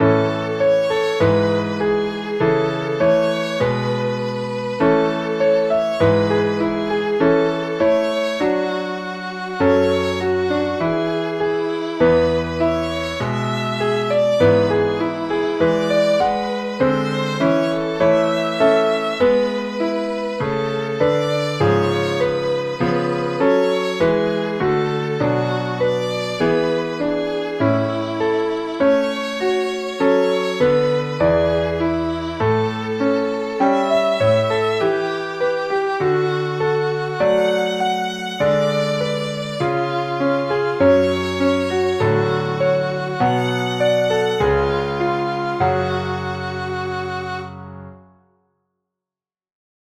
Doodle in A major